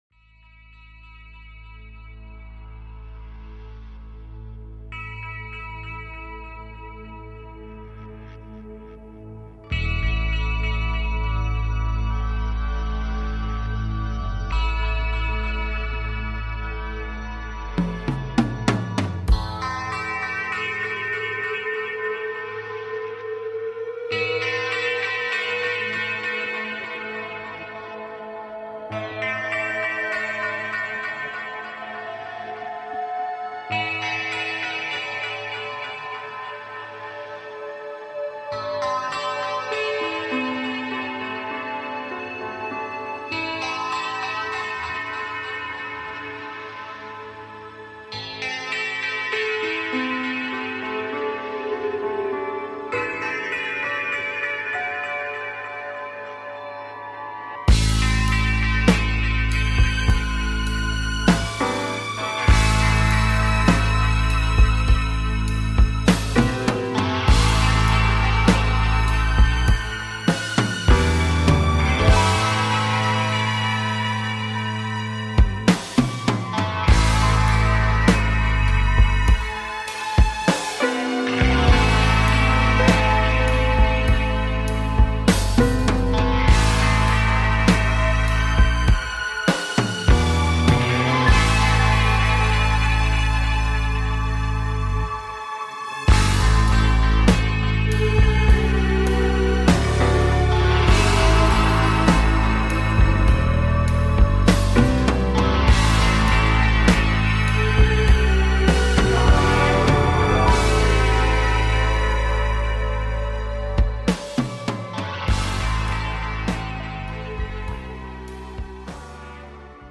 Talk Show Episode, Audio Podcast
A show based on Timelines and manifesting Timelines while taking caller questions.